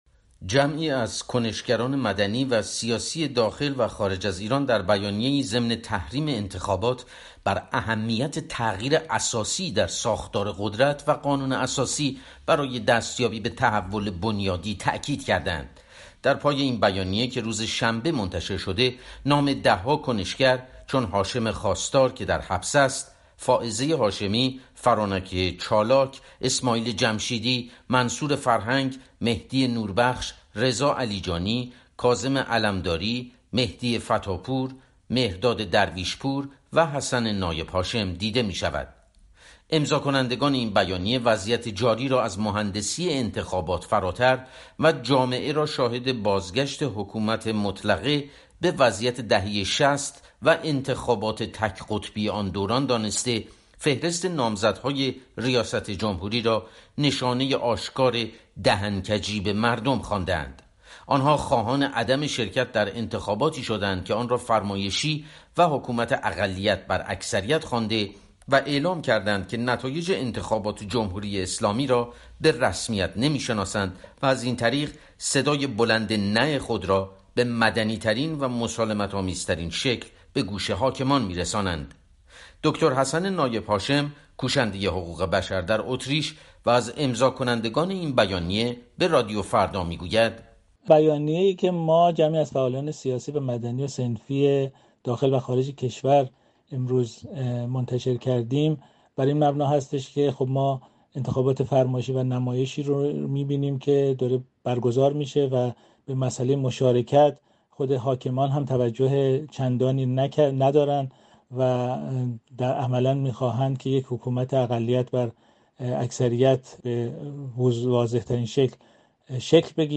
گزارشی